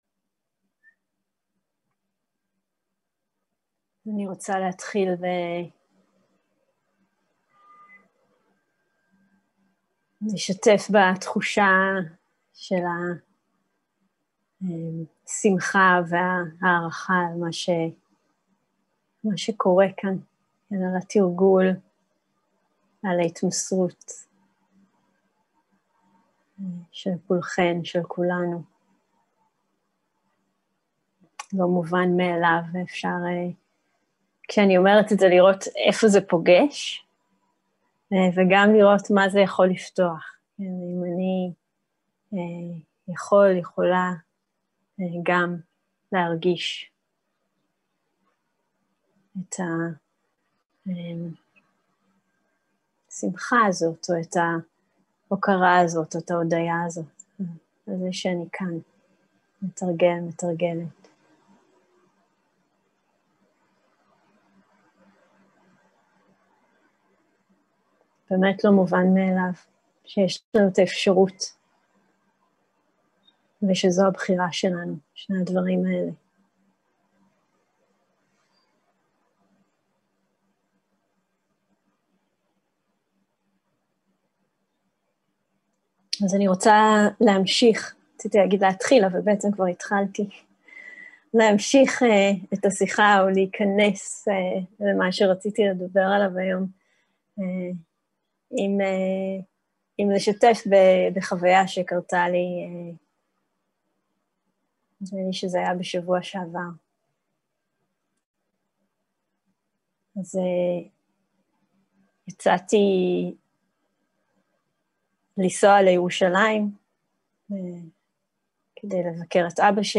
סוג ההקלטה: שיחות דהרמה
איכות ההקלטה: איכות גבוהה תגיות: חמשת האורחים Five hindrances